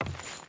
Wood Chain Walk 1.wav